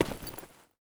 dc0f4c9042 Divergent / mods / Soundscape Overhaul / gamedata / sounds / material / human / step / default3.ogg 29 KiB (Stored with Git LFS) Raw History